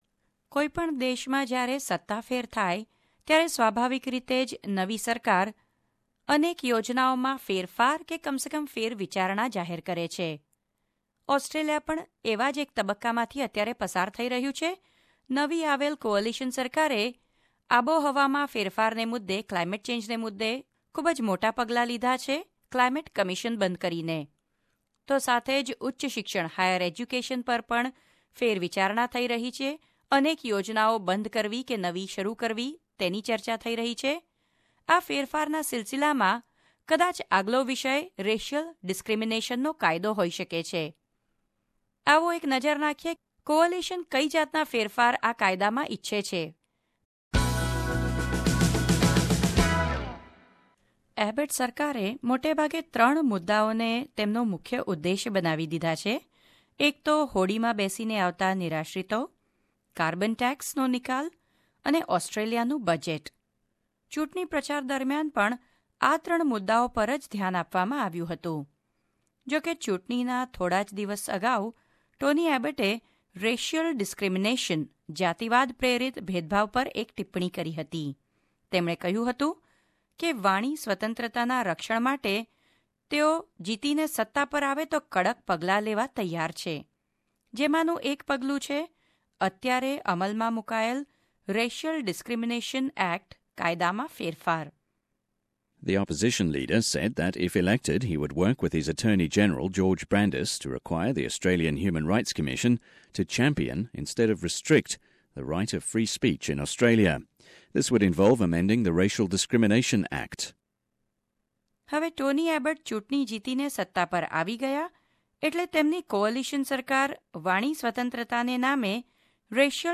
રજુ કરે છે વિગતવાર અહેવાલ .